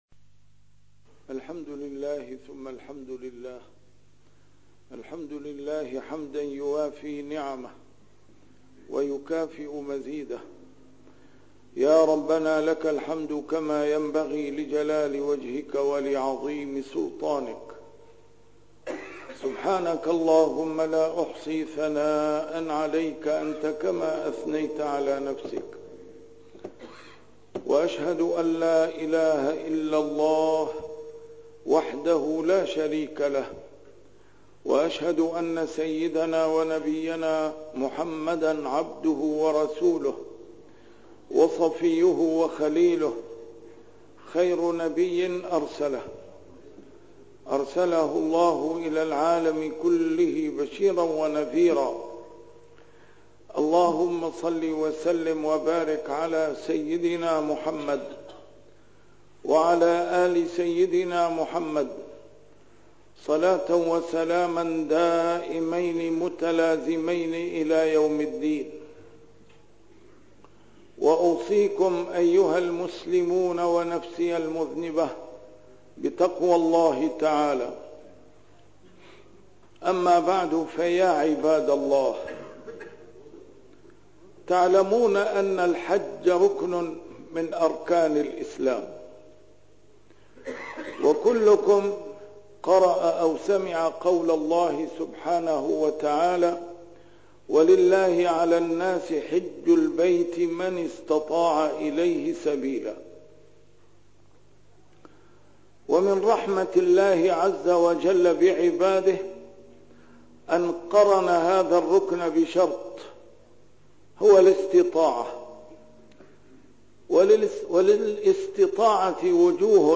A MARTYR SCHOLAR: IMAM MUHAMMAD SAEED RAMADAN AL-BOUTI - الخطب - حجاج.. لكنهم يعودون بالوزر لا بالأجر